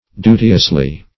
-- Du"te*ous*ly, adv.